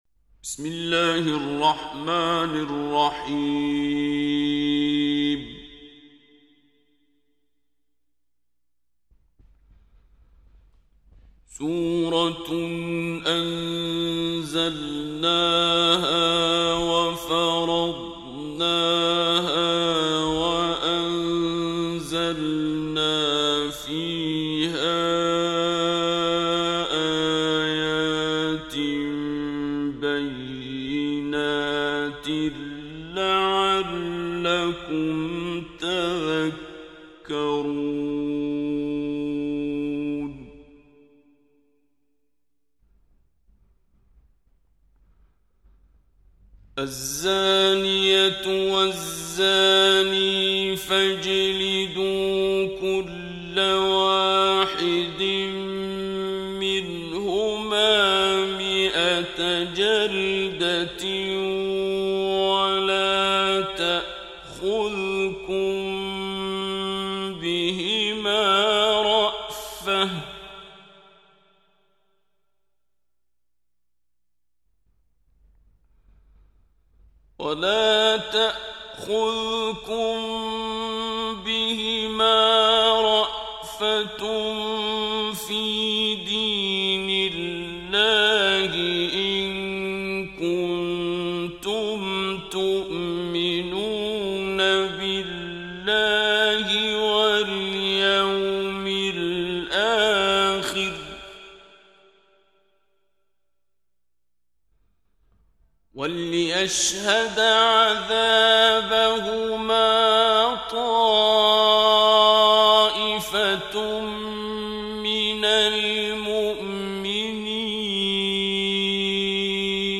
صوت | ترتیل قرآن کریم به صورت تجوید - استاد عبدالباسط (سری دوم)
ترتیل کل قرآن کریم با صدای استاد شیخ عبدالباسط عبدالصمد به تفکیک سوره های قرآن، با رعایت کامل قواعد تجویدی تقدیم مخاطبان قرآنی